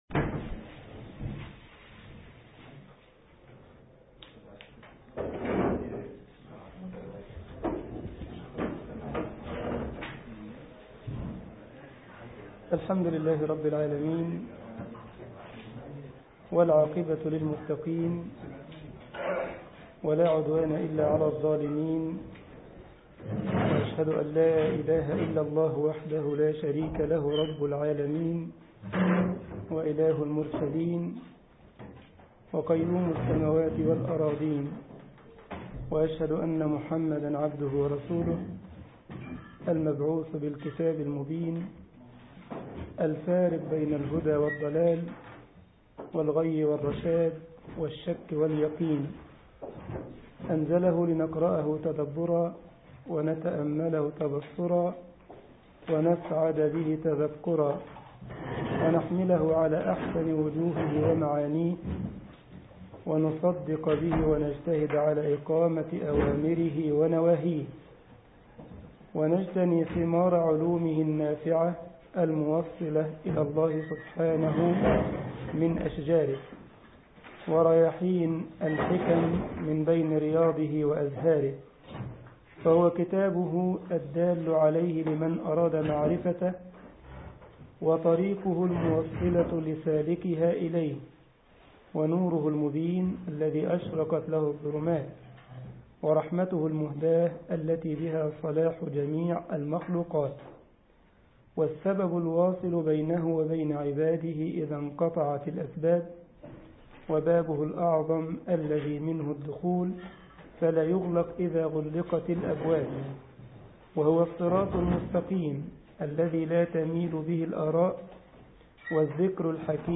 الجمعية الإسلامية بالسارلند ـ ألمانيا درس الأربعاء